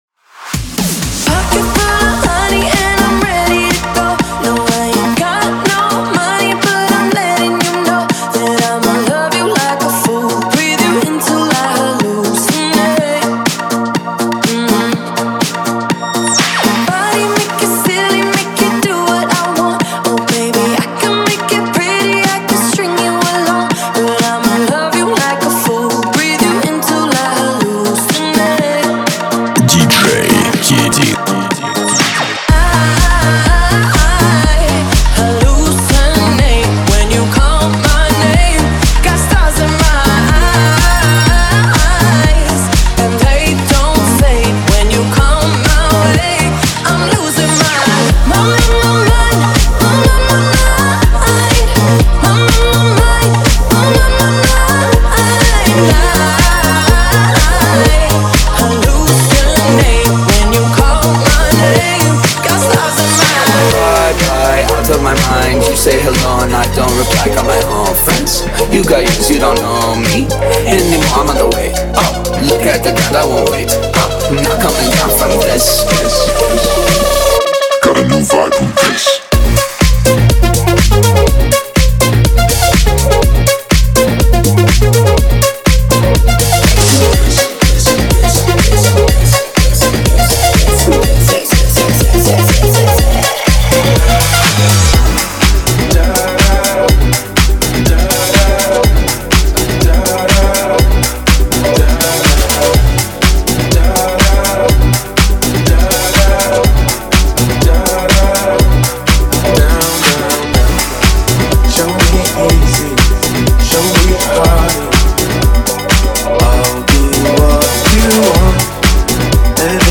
house
Отлично и драйвово!